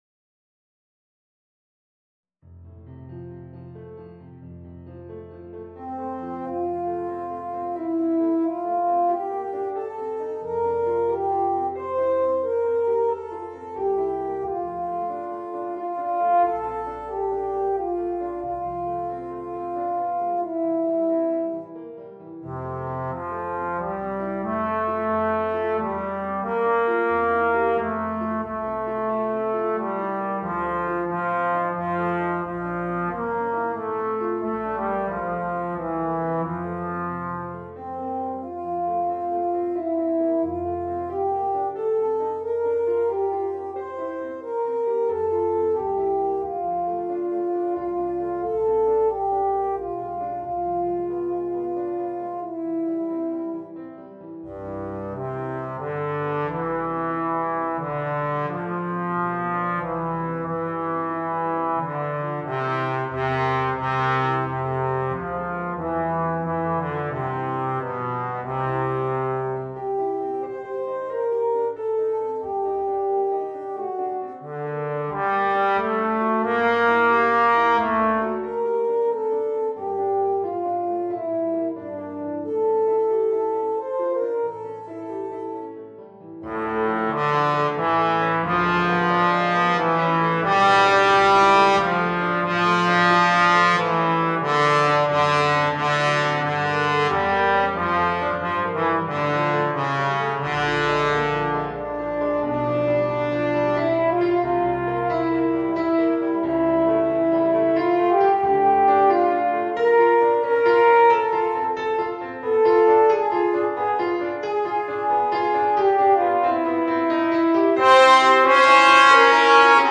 Voicing: Horn, Euphonium and Piano